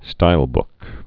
(stīlbk)